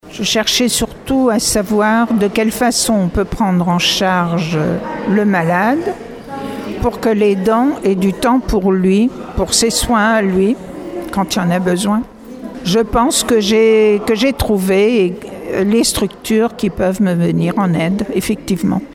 Le 2e Forum des aidants s’est tenu au palais des congrès de Rochefort.